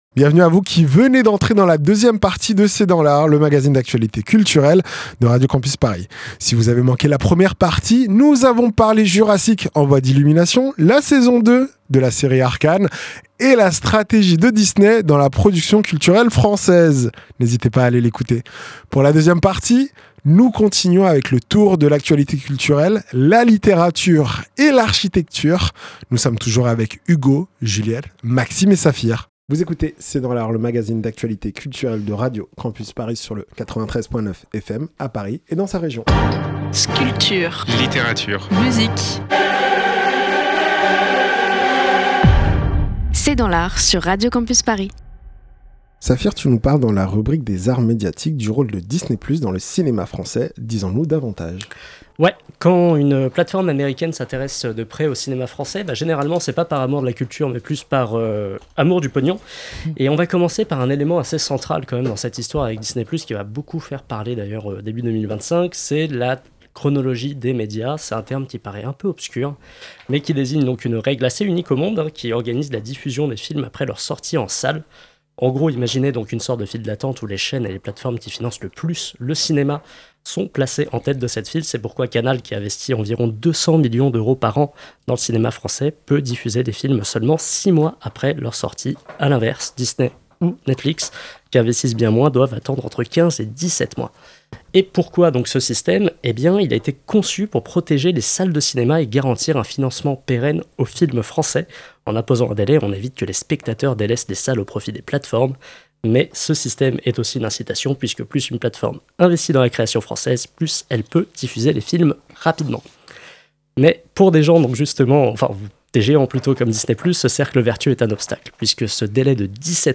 C'est dans l'art, c'est le nouveau magazine d'actualité culturelle de Radio Campus Paris